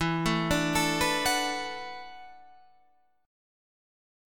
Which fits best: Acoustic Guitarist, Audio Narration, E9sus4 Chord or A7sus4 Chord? E9sus4 Chord